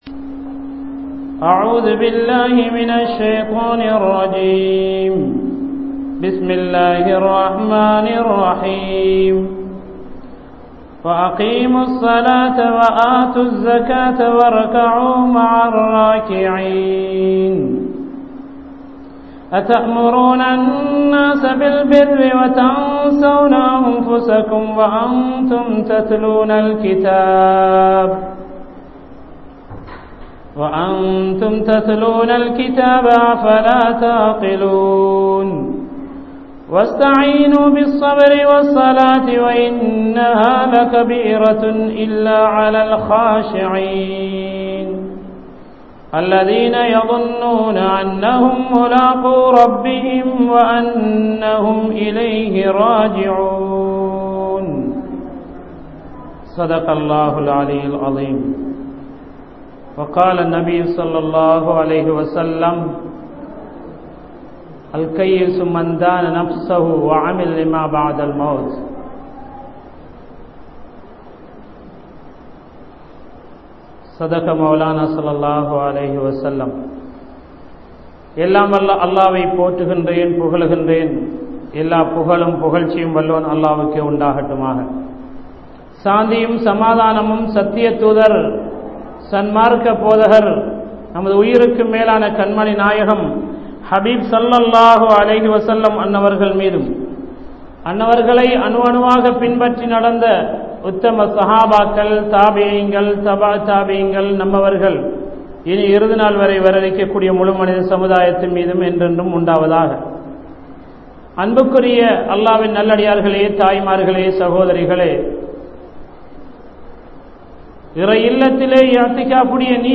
Nalla Pengalukkuriya Panpuhal (நல்ல பெண்களுக்குரிய பண்புகள்) | Audio Bayans | All Ceylon Muslim Youth Community | Addalaichenai